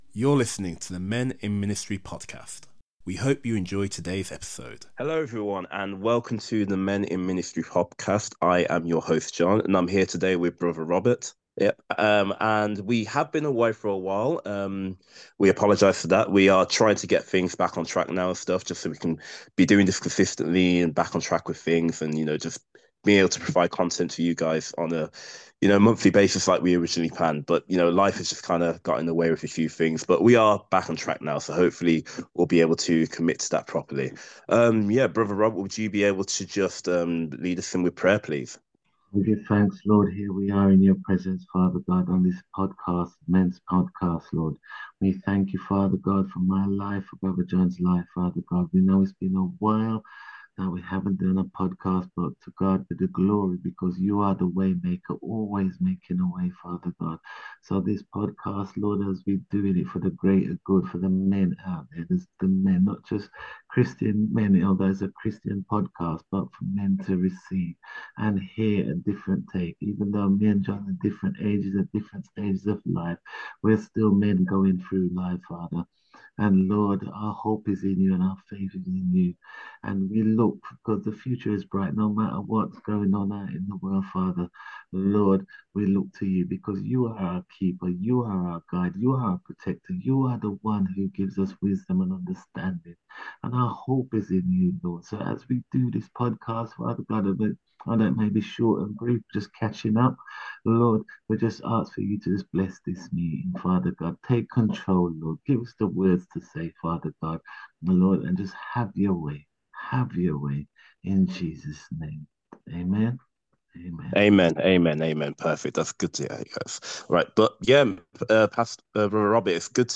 Together, they discuss the signs of menopause, how men can recognise them and ways to offer support to the women in their lives.